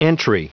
Prononciation du mot entry en anglais (fichier audio)
Prononciation du mot : entry